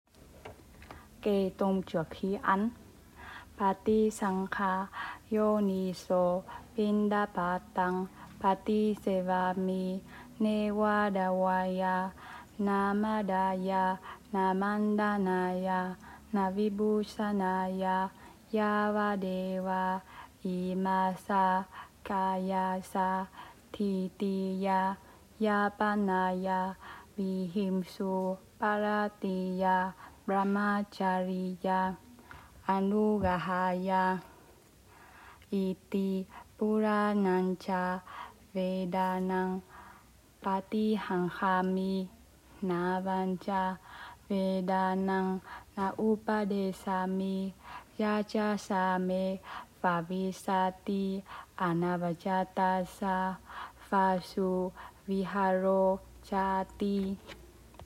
Kệ-tụng-trước-bữa-ăn.m4a